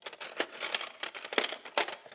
Babushka / audio / sfx / Kitchen / SFX_Cutlery_01_Reverb.wav
SFX_Cutlery_01_Reverb.wav